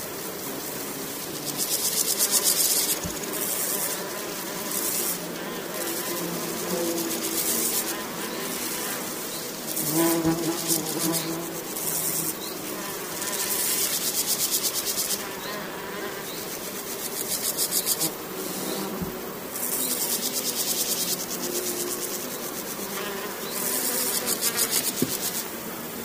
• grasshoppers - brush crickets insects sound.wav
grasshoppers_-_brush_crickets_insects_sound-2_Xtl.wav